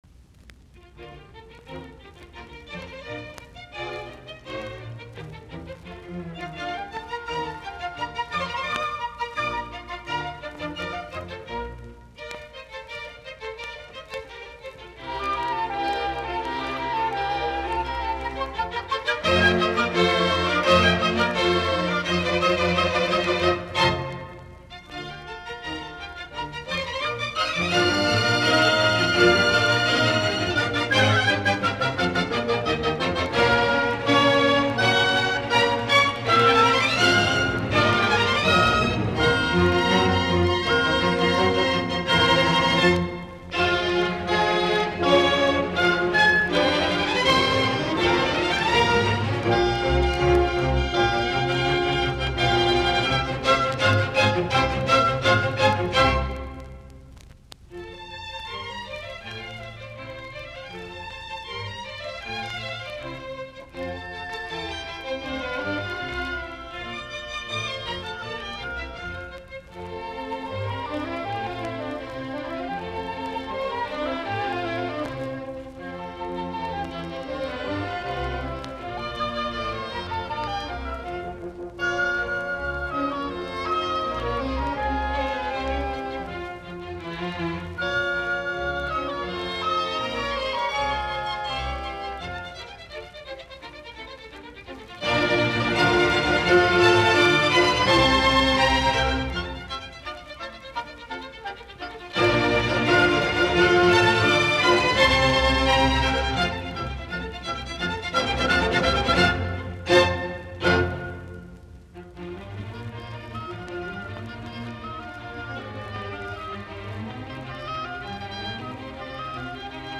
musiikkiäänite
Allegro vivace